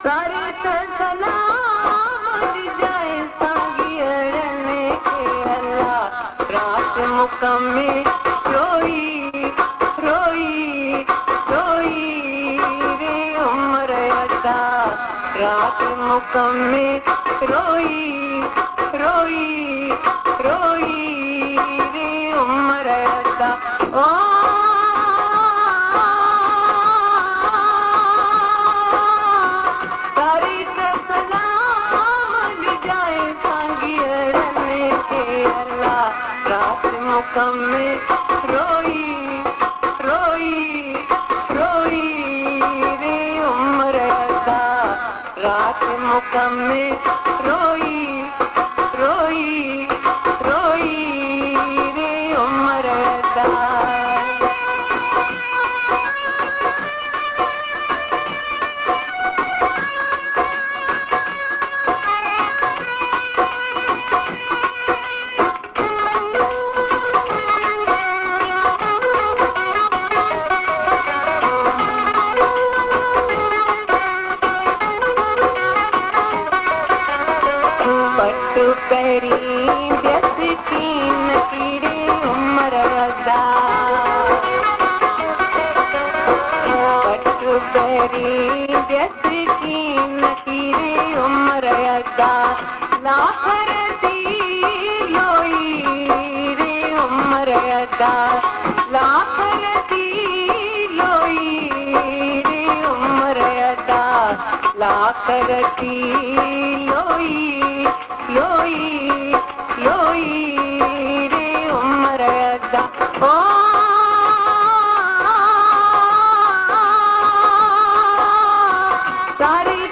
Sindhi songs